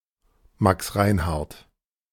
Max Reinhardt (German: [maks ˈʁaɪnhaʁt]
De-Max_Reinhardt.ogg.mp3